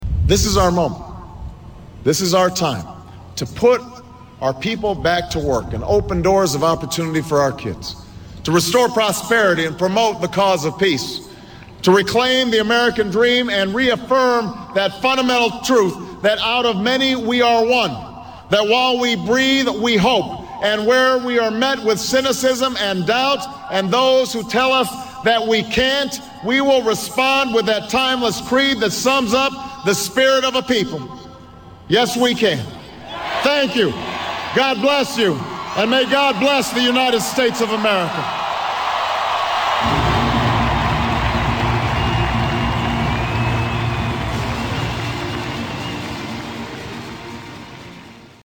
Tags: Barack Obama Barack Obama speech Barack Obama clips US President History